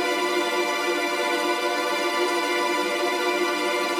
GS_TremString-Bdim.wav